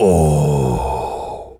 gorilla_growl_deep_03.wav